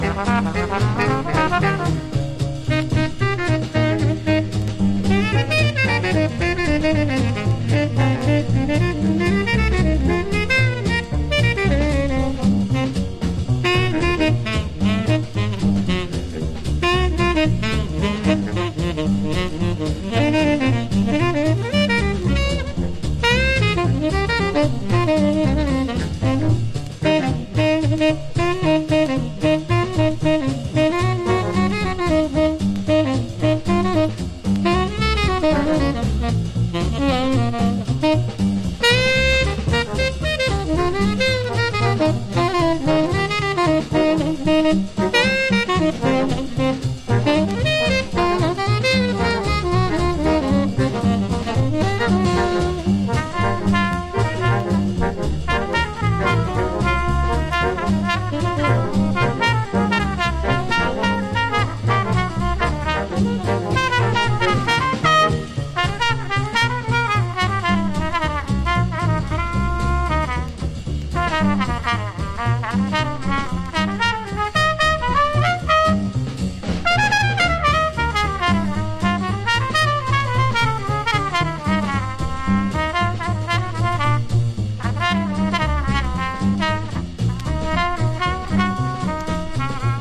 3管のアレンジが実に豊かで多彩、心躍らせてくれるようです。
MODERN JAZZ